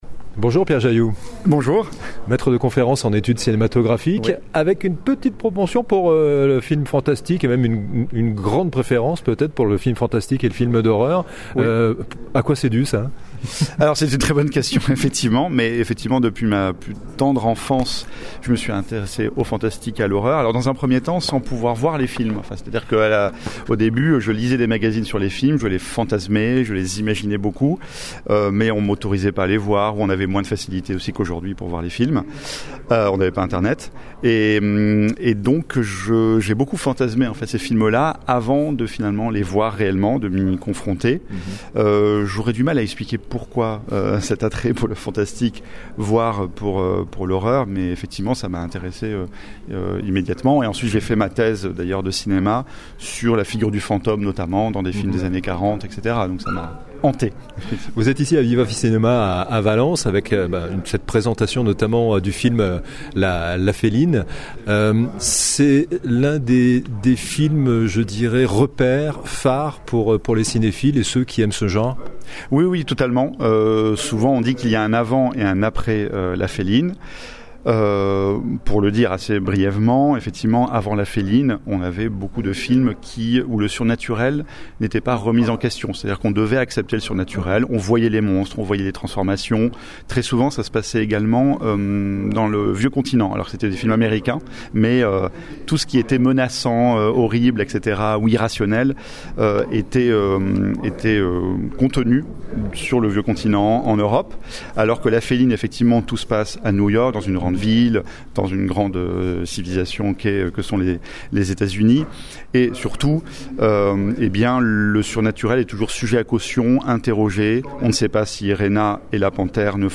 2. Podcasts cinéma : interviews | La Radio du Cinéma